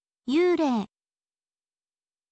yuurei